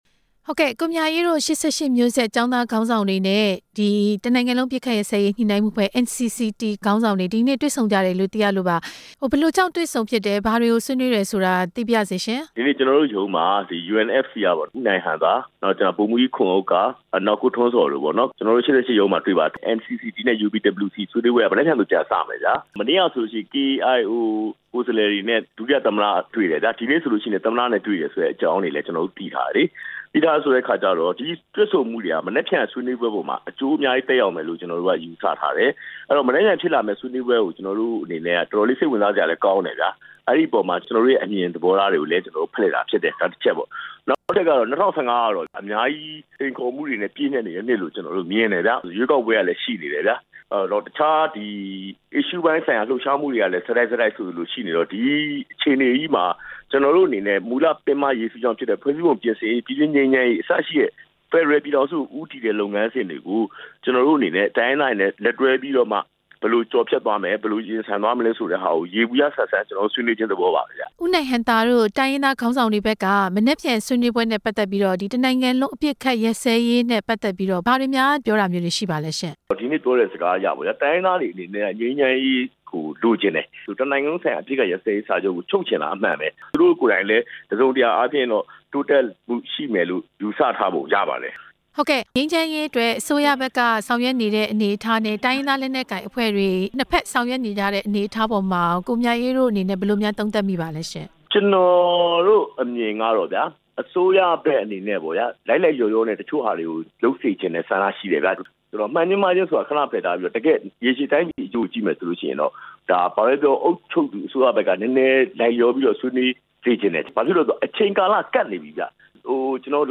UNFC နဲ့ ၈၈ အဖွဲ့တို့ တွေ့ဆုံခဲ့တဲ့ အကြောင်း မေးမြန်းချက်